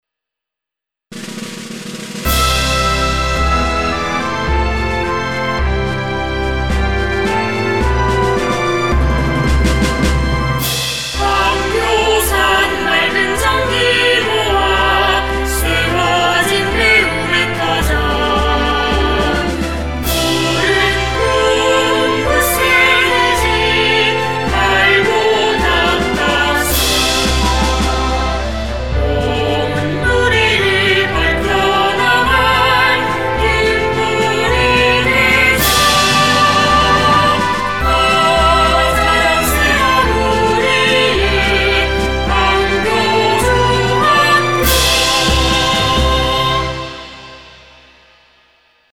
교가